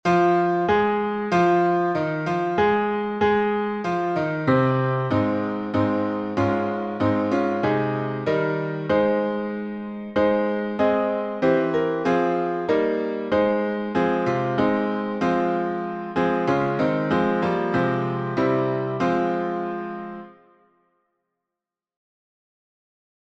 Words from “Sing Psalms” Tune: RESTORATION by Joseph Hart, 1759 Key signature: F minor (4 flats) Time s